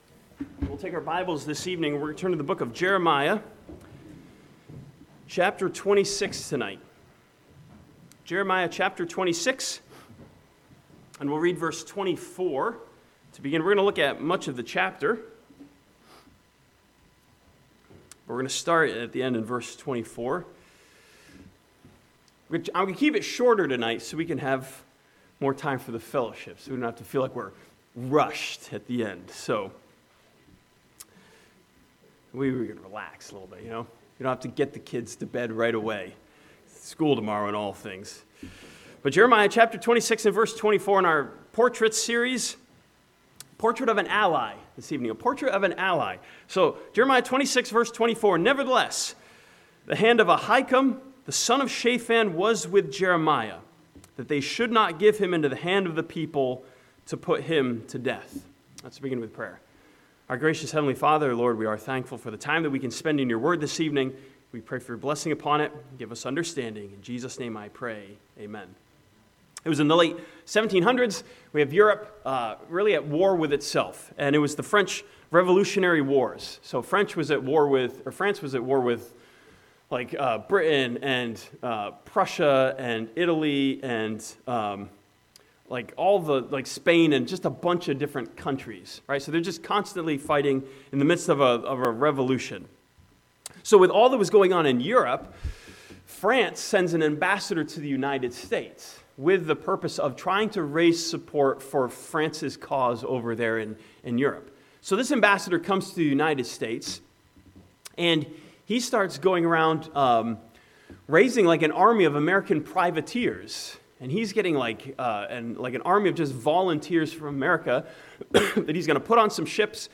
This sermon from Jeremiah chapter 26 challenges believers with the portrait of an ally who choose to side with God.